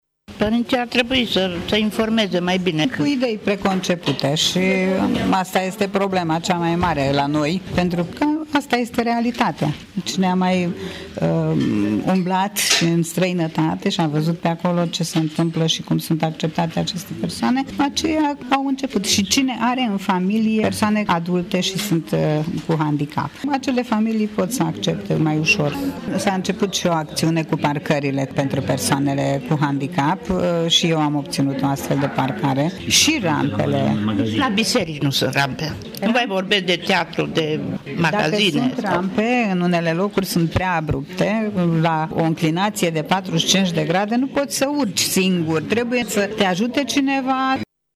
Cei intervievati de reporterul Radio Tirgu-Mures au recunoscut că sunt destul de dificil de identificat persoanele cu dizabilități pentru că unele familii își ascund, de rușine, copiii care se nasc cu asemenea handicap.